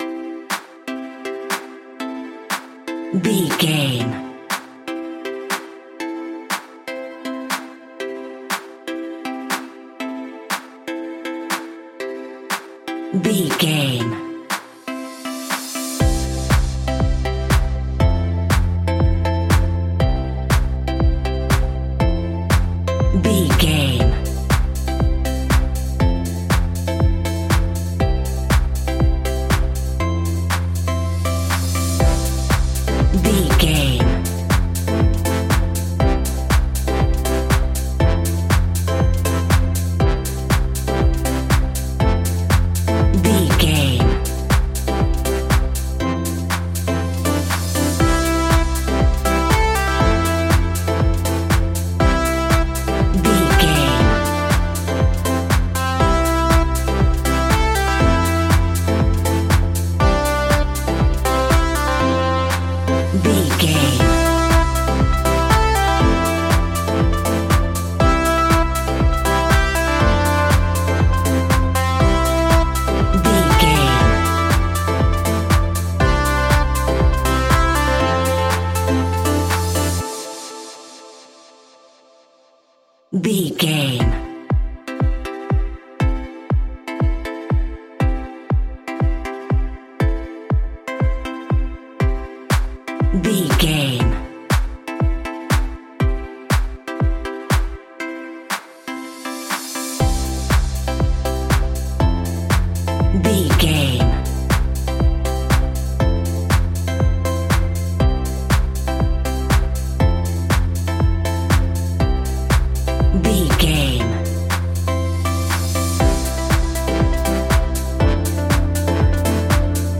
Ionian/Major
groovy
dreamy
smooth
drum machine
synthesiser
funky house
deep house
nu disco
upbeat
funky guitar
clavinet
fender rhodes
synth bass
funky bass
horns